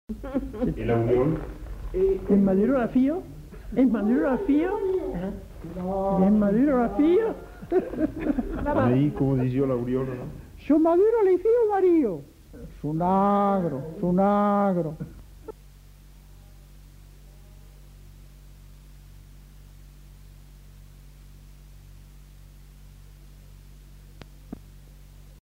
Aire culturelle : Haut-Agenais
Effectif : 1
Type de voix : voix d'homme
Production du son : récité
Classification : mimologisme